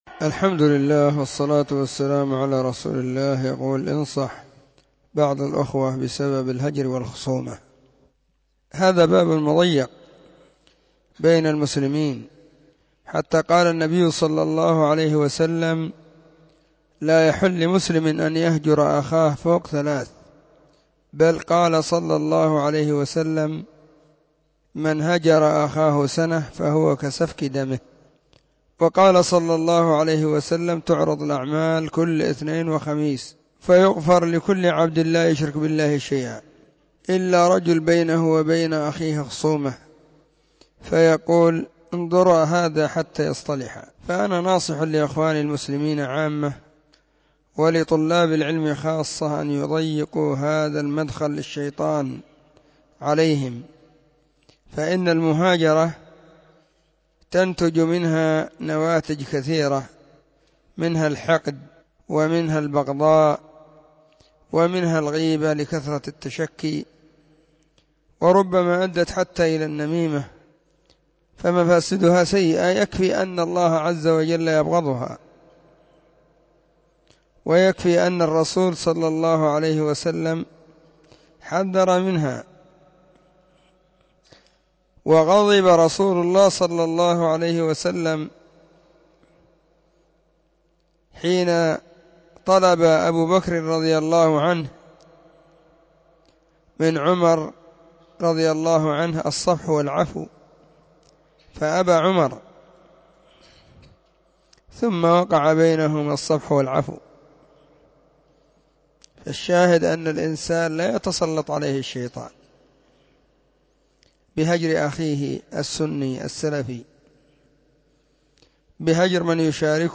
سلسلة الفتاوى الصوتية